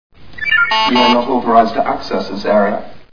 Sfx: Warning alarm.